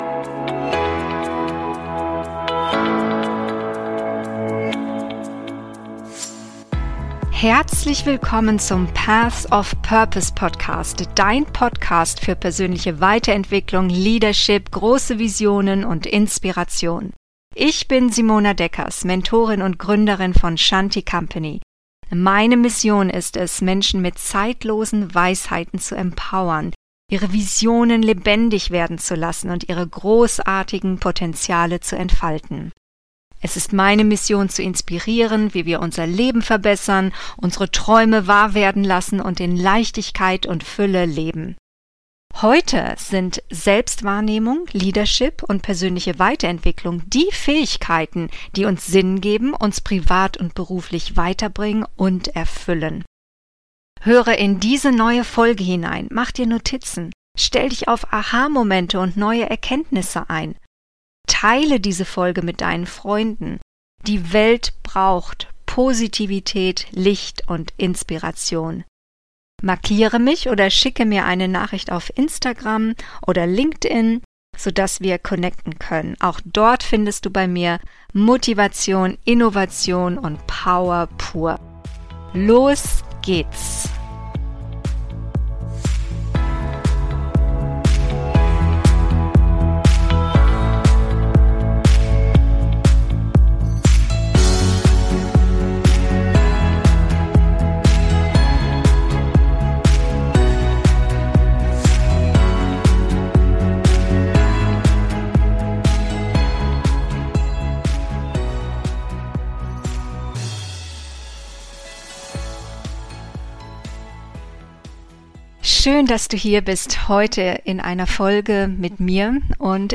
Dranbleiben für Erfolg - Solofolge